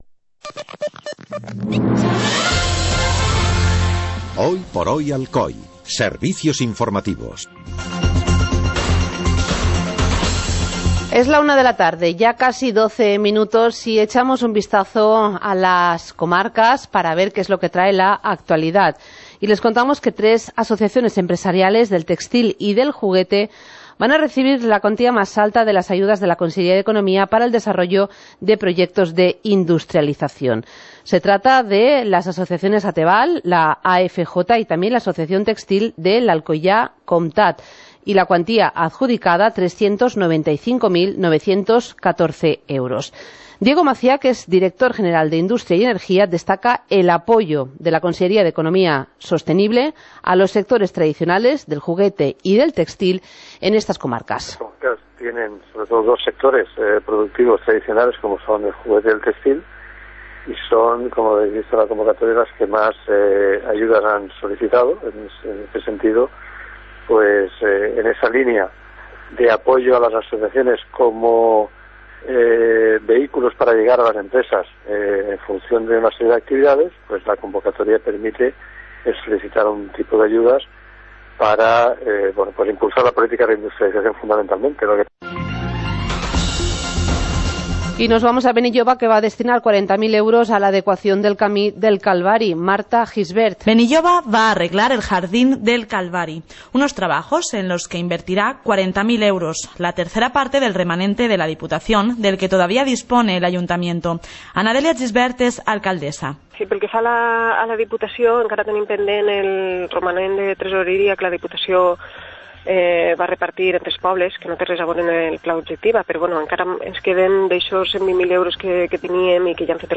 Informativo comarcal - martes, 27 de diciembre de 2016